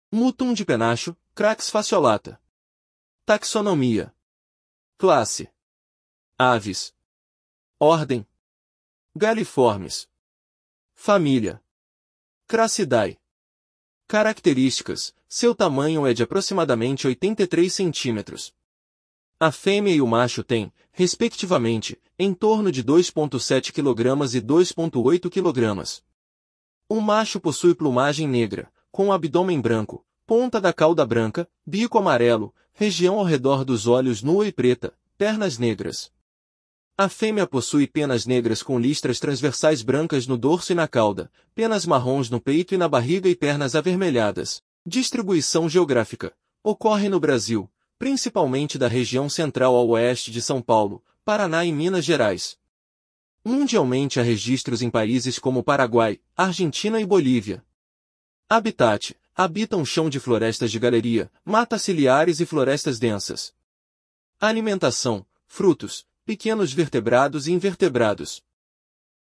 Mutum-de-penacho (Crax fasciolata)
Classe Aves